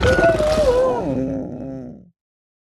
Minecraft Version Minecraft Version snapshot Latest Release | Latest Snapshot snapshot / assets / minecraft / sounds / mob / sniffer / death2.ogg Compare With Compare With Latest Release | Latest Snapshot